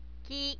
ki -
ki.wav